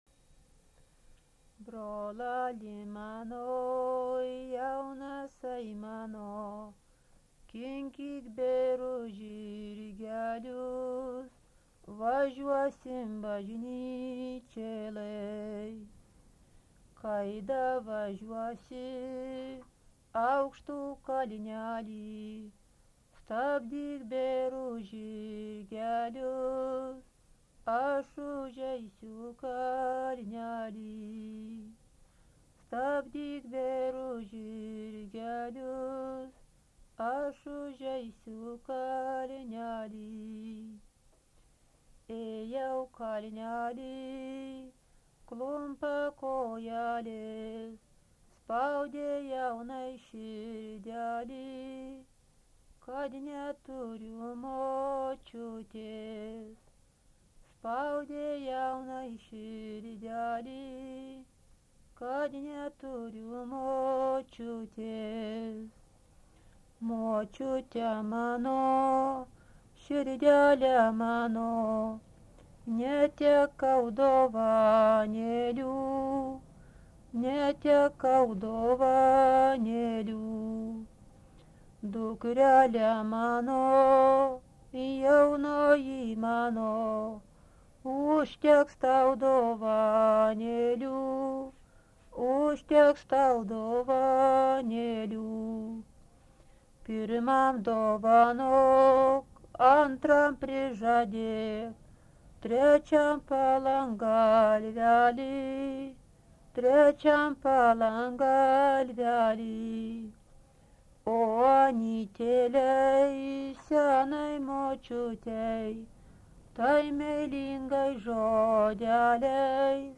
daina